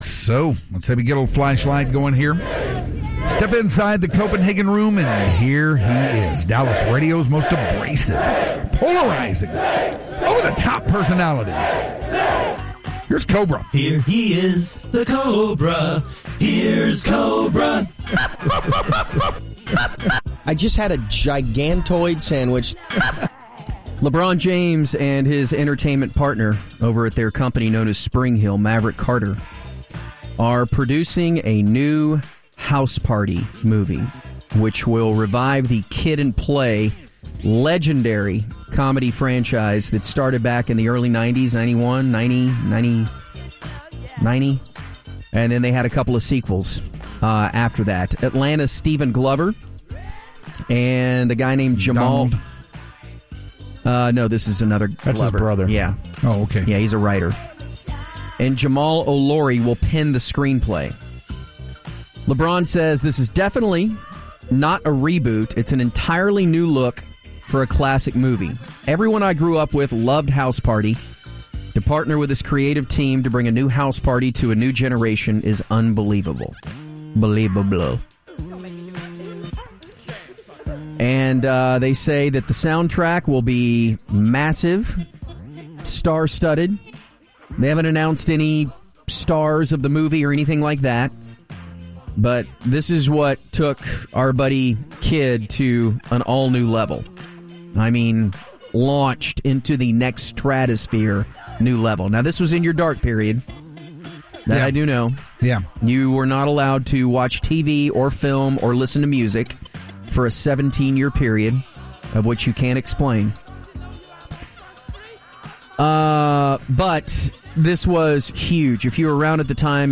The Hardline gets a call from Kid to talk about the new House Party movie he may or may not be in.